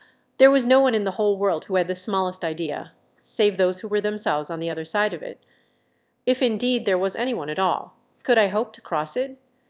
audio-to-audio voice-conversion
Voice conversion with soft speech units